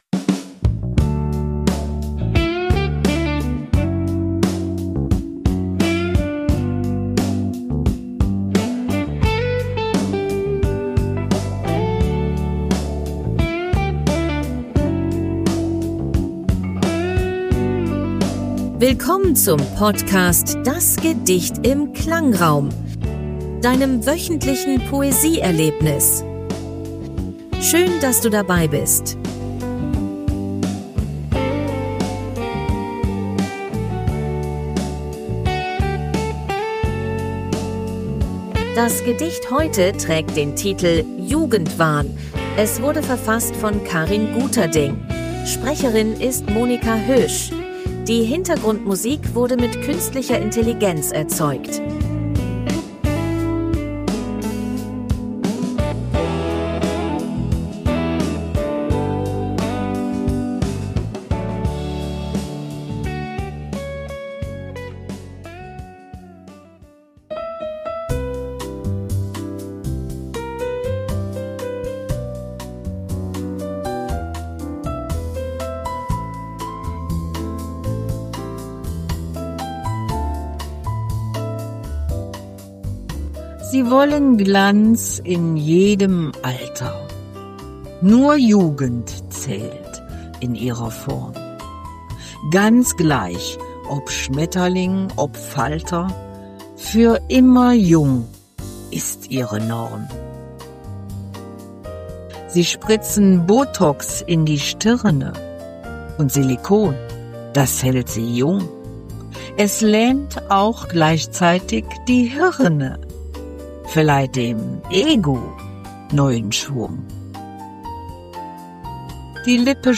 Die Hintergrundmusik wurde mit KI